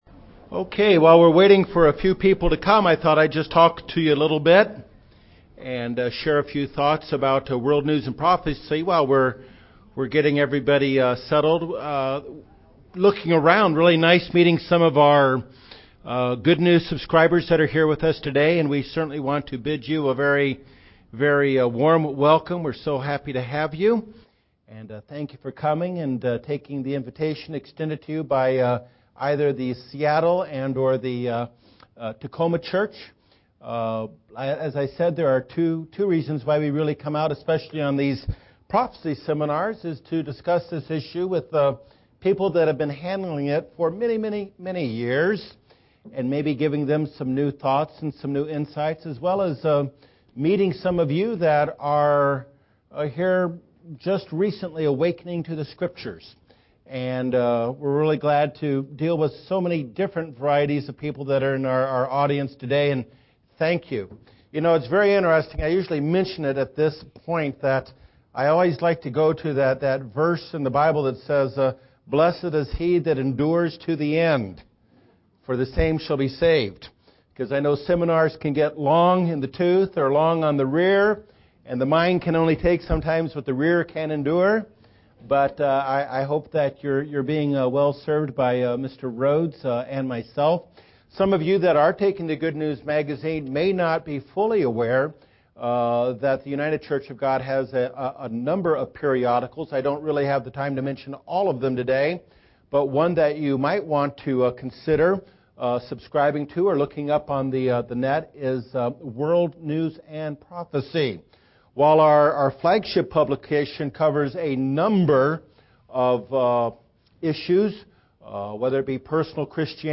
World News and Prophecy Seminar Message